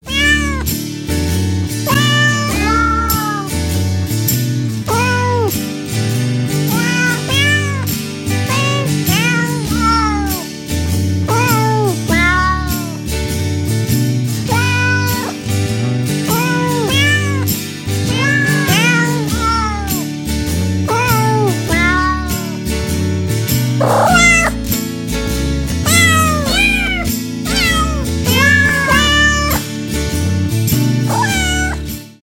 звуки животных